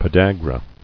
[po·dag·ra]